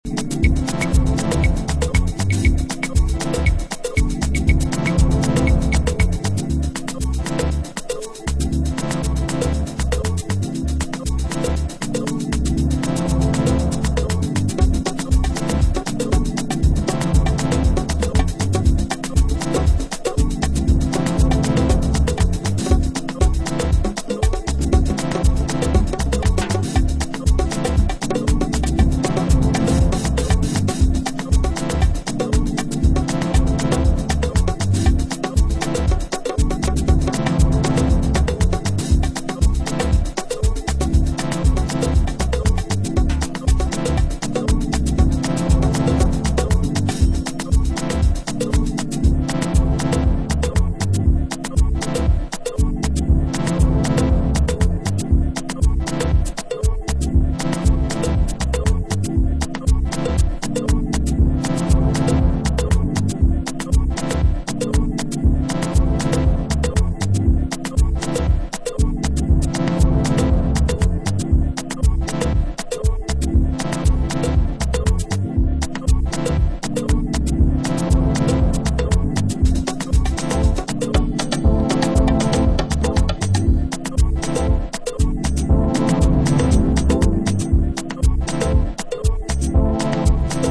deep, afro inspired underground techno soul